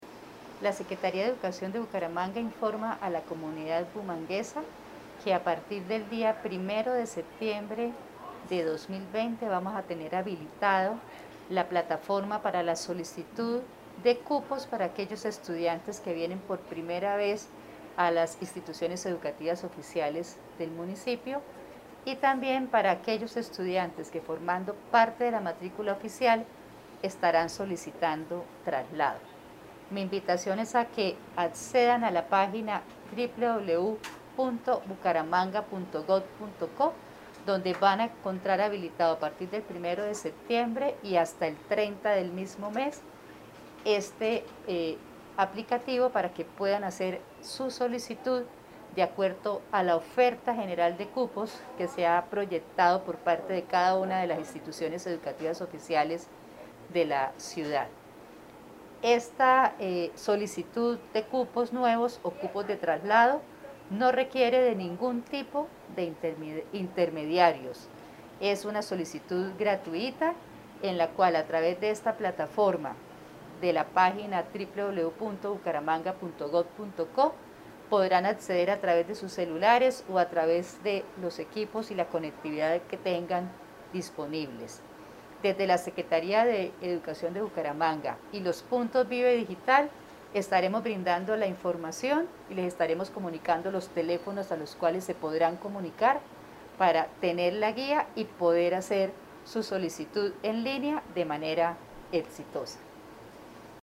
Descargar audio: Ana Leonor Rueda Vivas, secretaria Educación Bucaramanga
Ana-Leonor-Rueda-secretaria-educacion-Bga.mp3